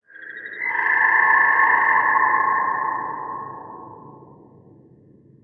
Index of /garrysmod/addons/hl2ep2_content_gmodcontent/sound/ambient/levels/caves